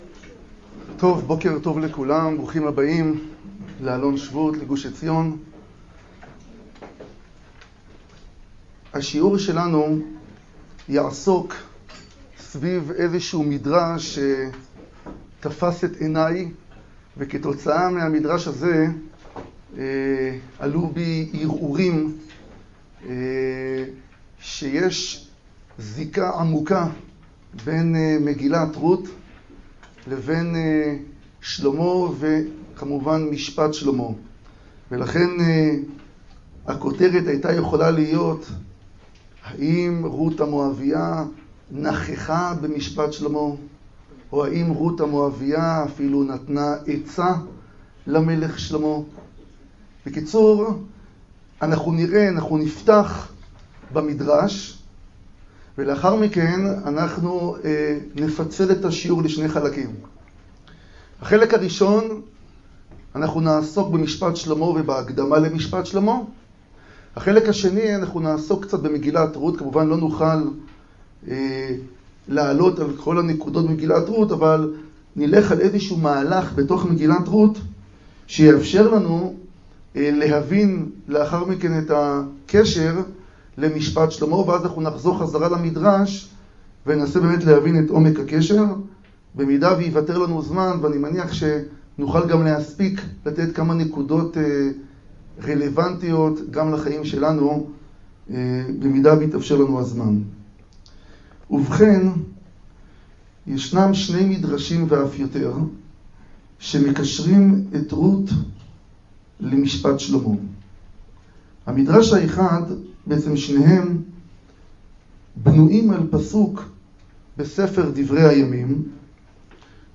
השיעור באדיבות אתר התנ"ך וניתן במסגרת ימי העיון בתנ"ך של המכללה האקדמית הרצוג